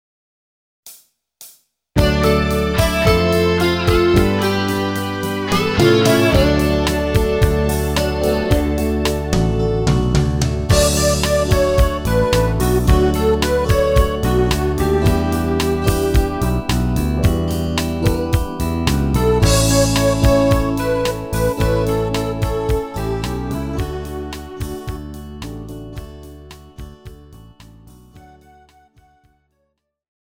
KARAOKE/FORMÁT:
MP3 ukázka s ML